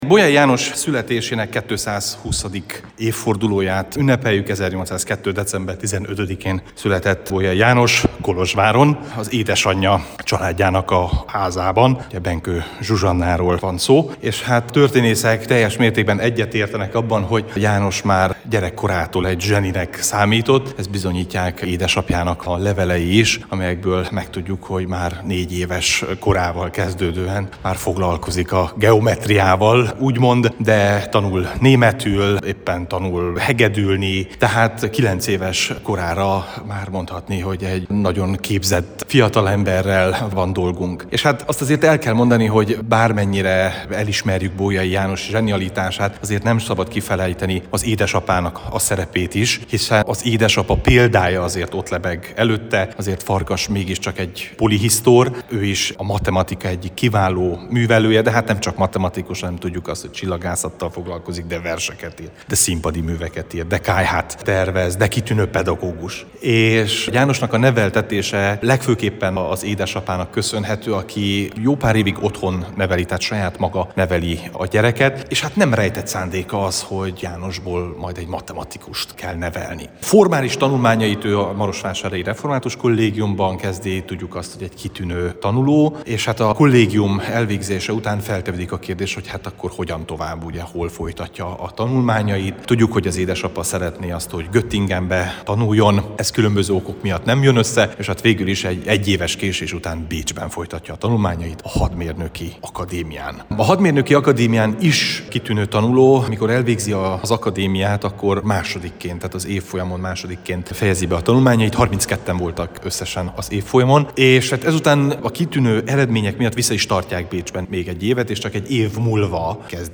történész